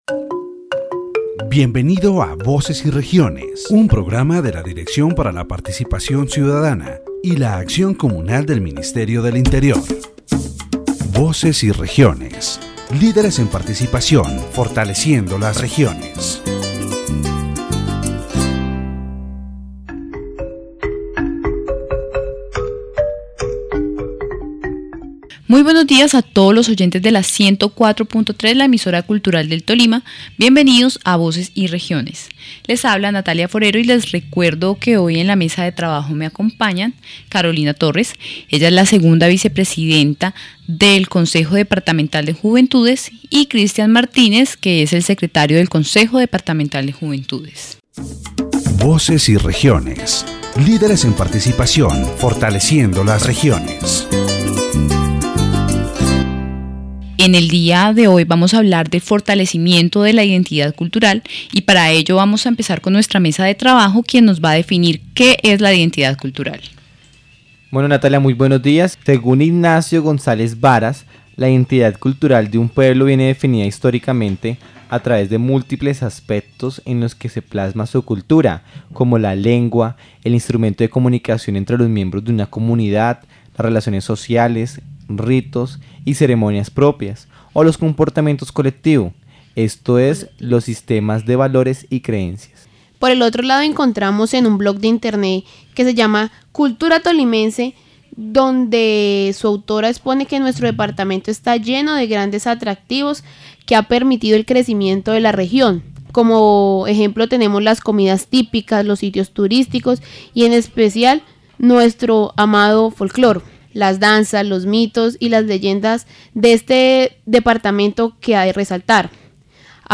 In this edition of Voces y Regiones, the concept of cultural identity and the elements that strengthen it in the department of Tolima are explored. The interviewees highlight Tolima’s rich heritage, emphasizing its natural, climatic, and cultural diversity, which have been key in shaping its identity.